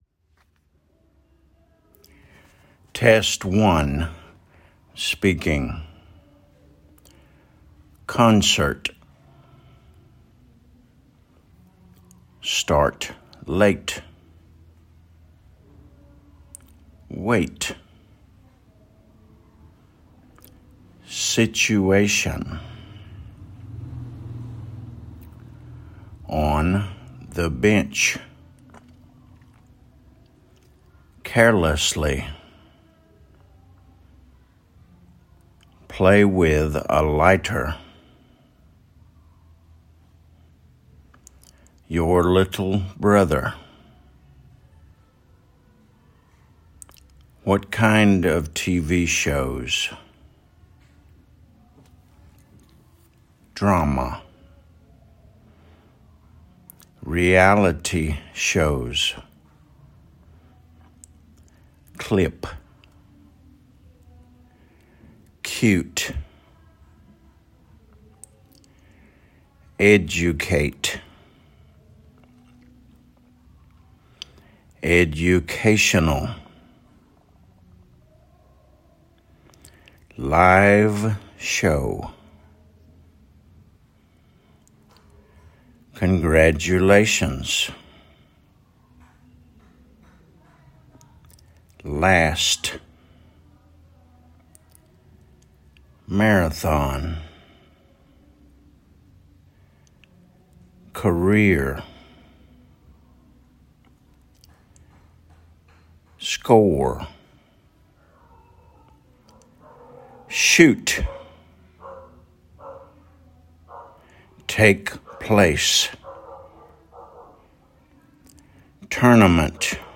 concert /ˈkɒn.sət/
situation /ˌsɪtʃ.ʊˈeɪ.ʃən/
congratulations /kənˌɡrætjʊˈleɪʃənz/